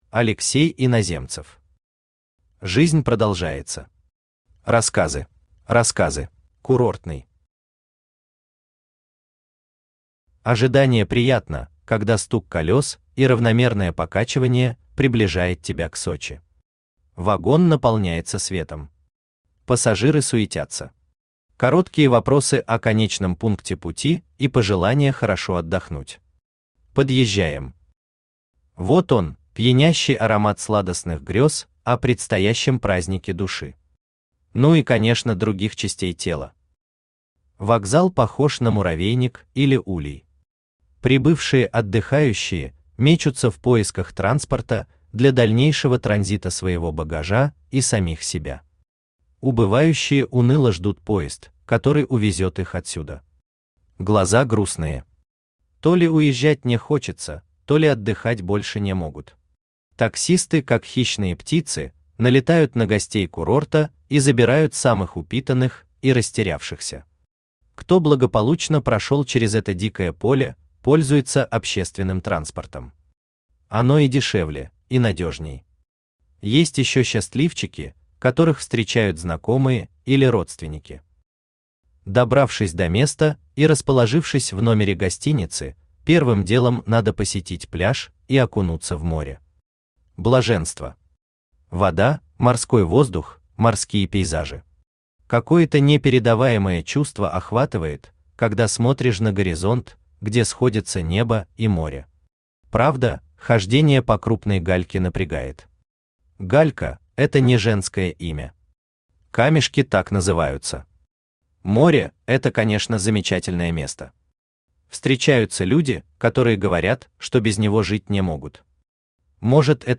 Аудиокнига Жизнь продолжается. Рассказы | Библиотека аудиокниг
Рассказы Автор Алексей Васильевич Иноземцев Читает аудиокнигу Авточтец ЛитРес.